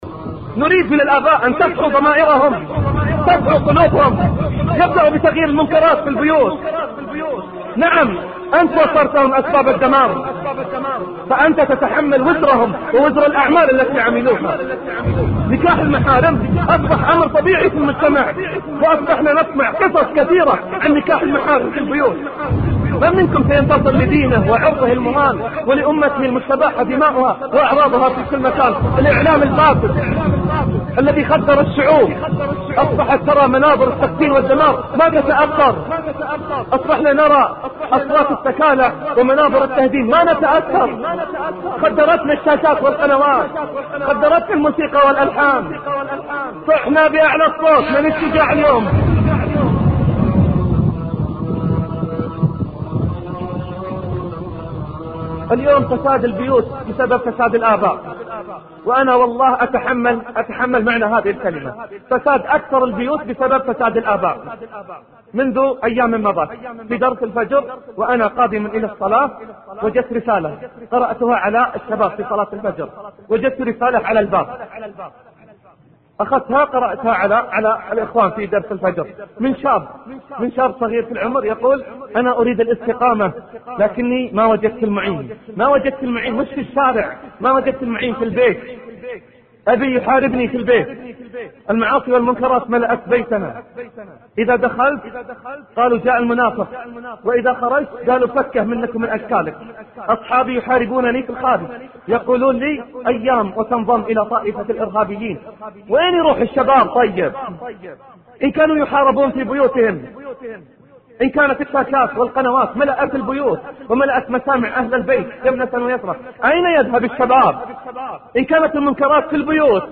الباب الثامن: الدعوة للشجاعة والإصلاح رفع الشيخ صوته داعيًا الآباء للشجاعة في مواجهة المنكرات، وأن يطهروا بيوتهم من الأطباق والقنوات.
المحاضرات الصوتية